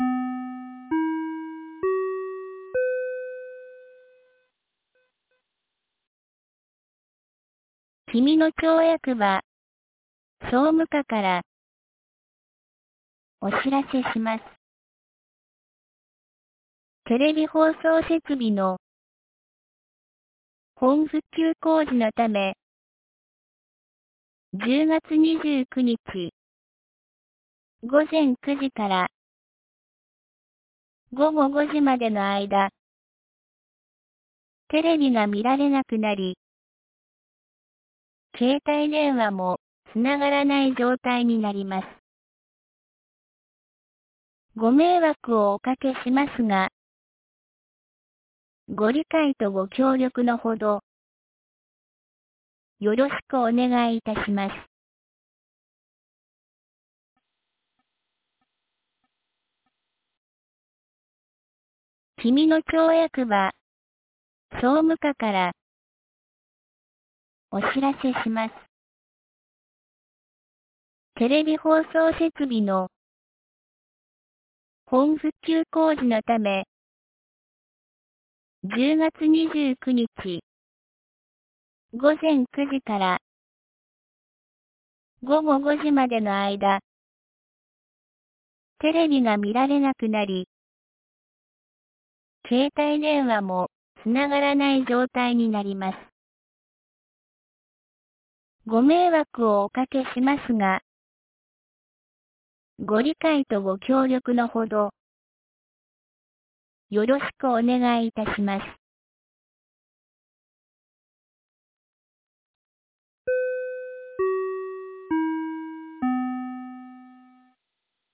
2024年10月25日 17時17分に、紀美野町より国吉地区へ放送がありました。